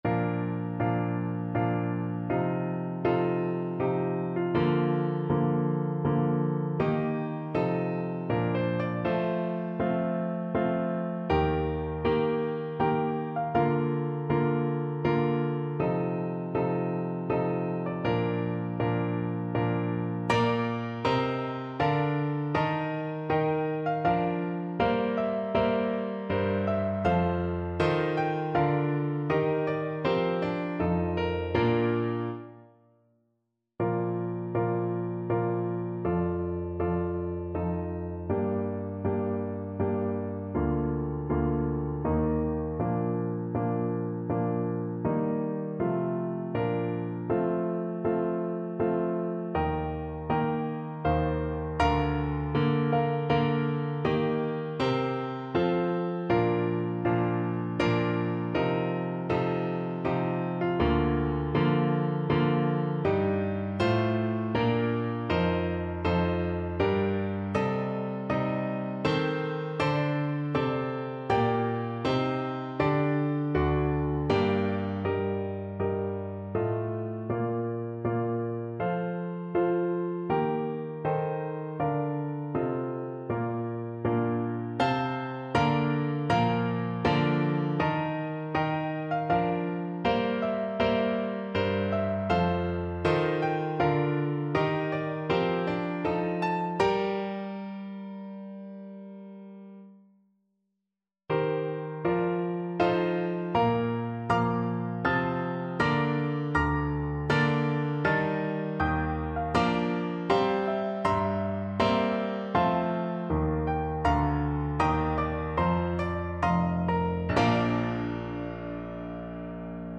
Andante grandioso
3/4 (View more 3/4 Music)
Classical (View more Classical Tenor Saxophone Music)